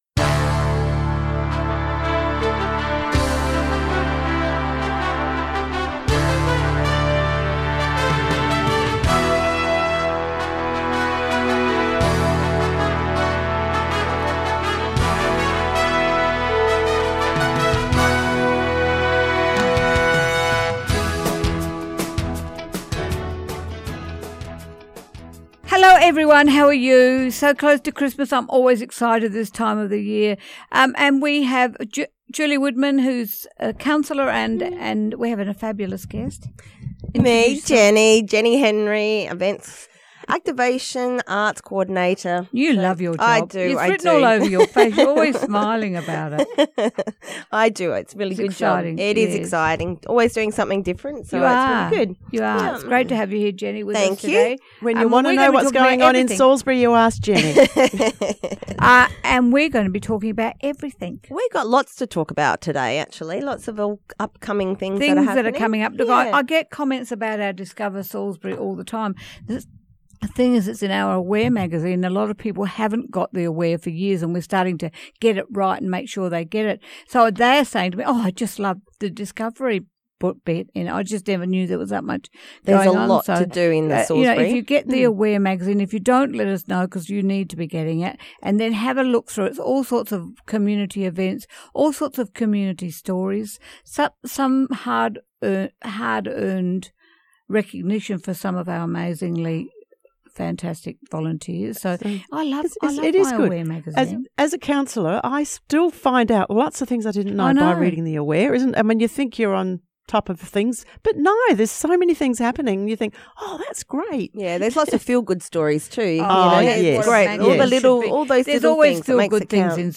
Listen to the latest episode of Spotlight on Salisbury, broadcast on December 7th at 10:30am, as presented by the Mayor of the City of Salisbury, Gillian Aldridge